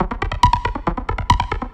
synth02.wav